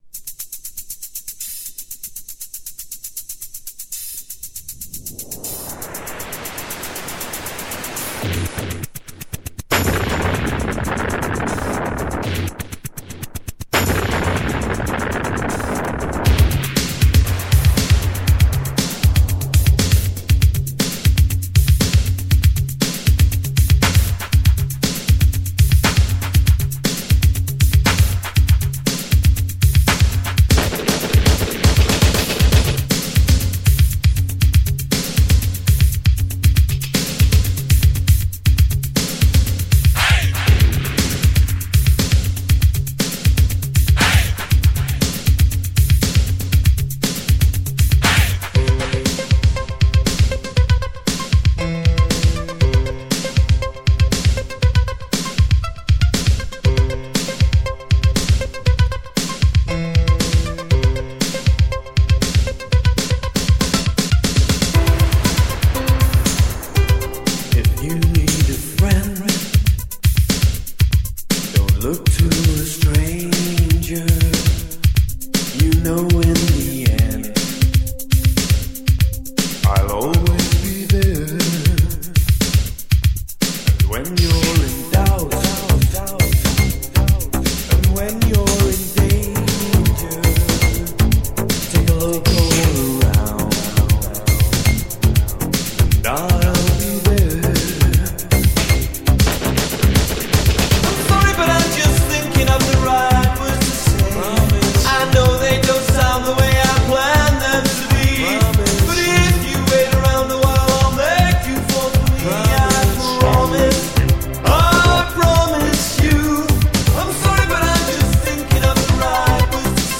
Типа Indie Pop, New Wave.